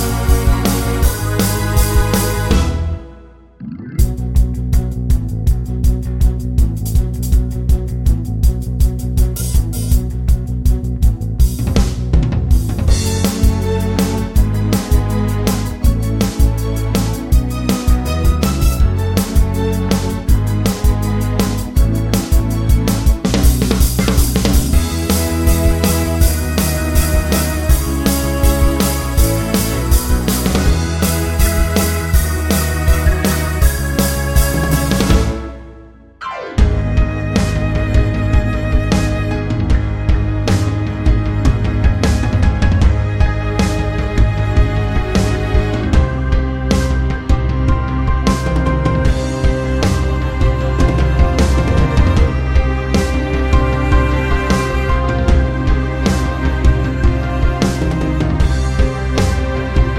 no Backing Vocals Soundtracks 2:01 Buy £1.50